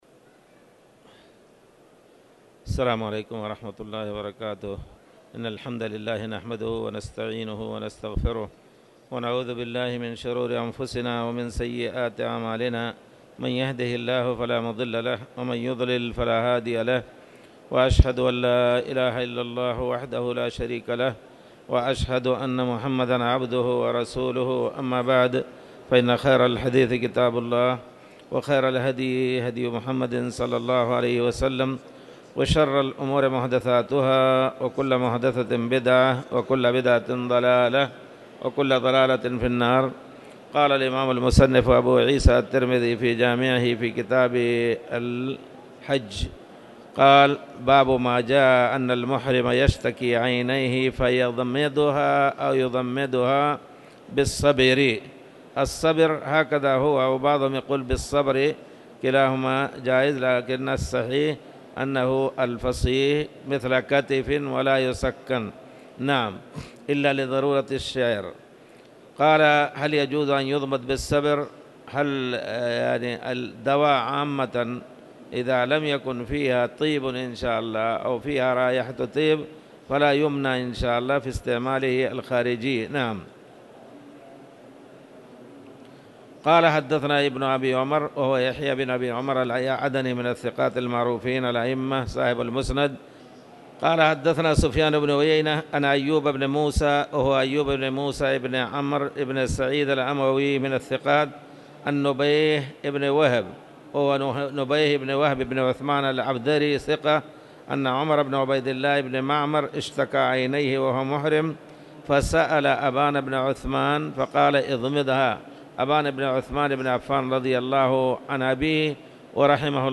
تاريخ النشر ٤ رمضان ١٤٣٧ المكان: المسجد الحرام الشيخ